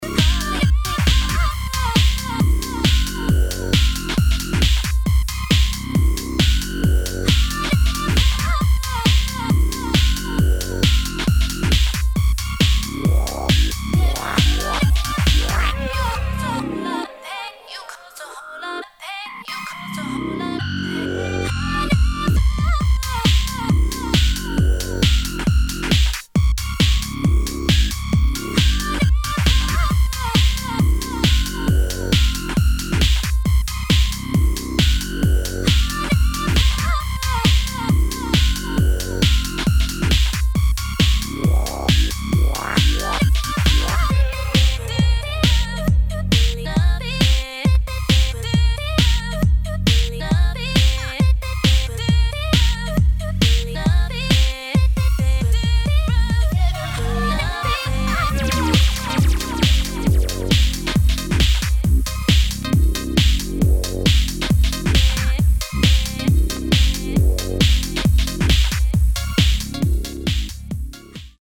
[ UK GARAGE ]